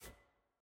sfx-jfe-ui-generic-hover.ogg